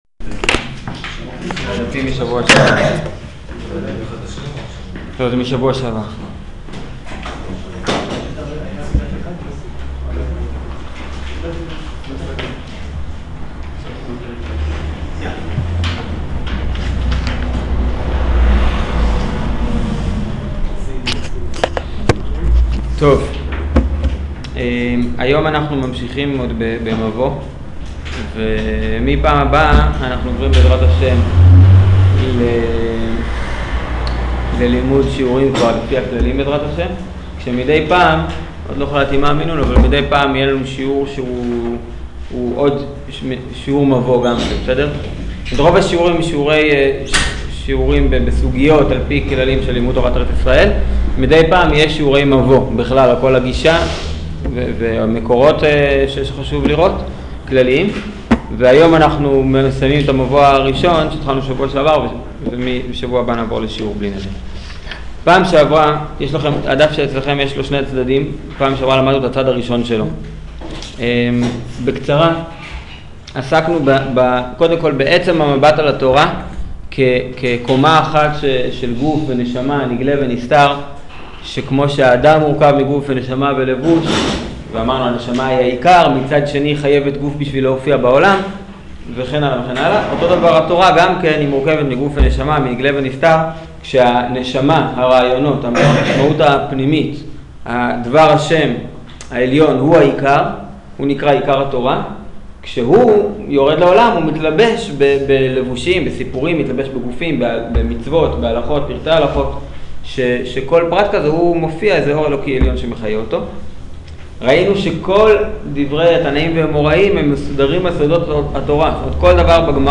שיעור מבוא חלק ב'